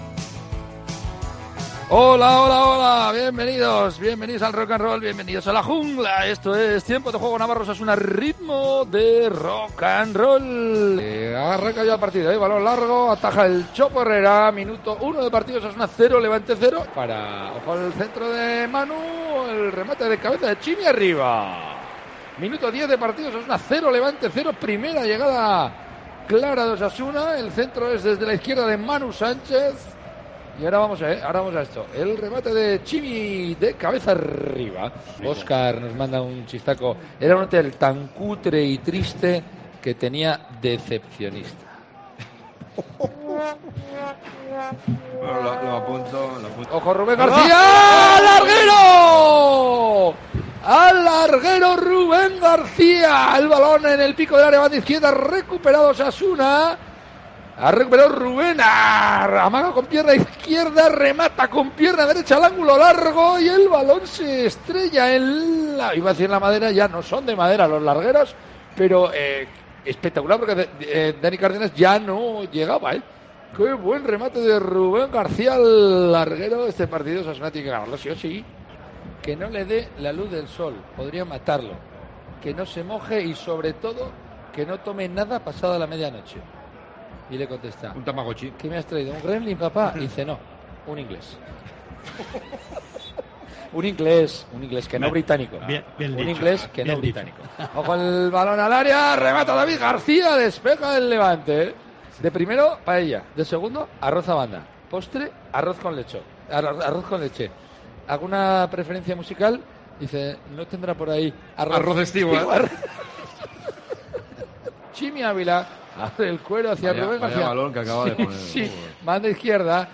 Revive la victoria de Osasuna ante el Levante con los mejores momentos de la retransmisión del partido en Cope +